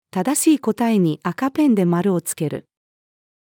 正しい答えに赤ペンで丸をつける。-female.mp3